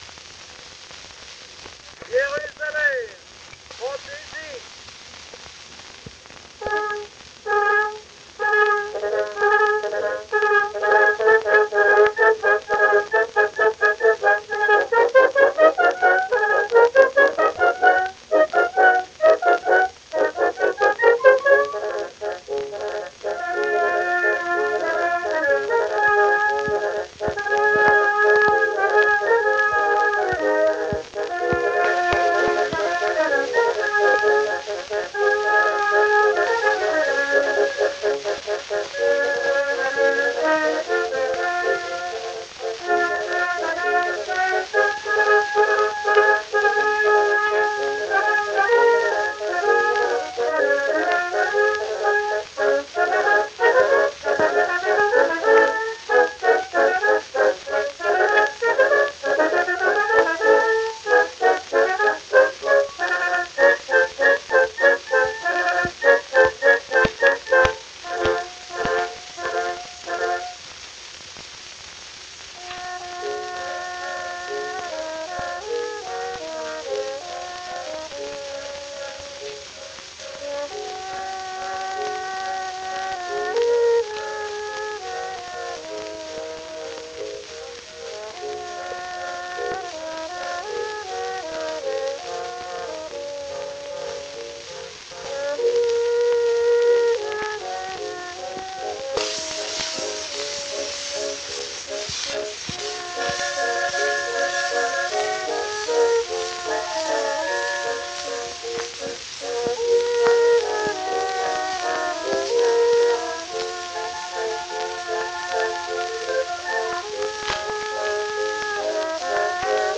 Unbekannte Bläsergruppe: Jérusalem Fantaisie (nach Giuseppe Verdi).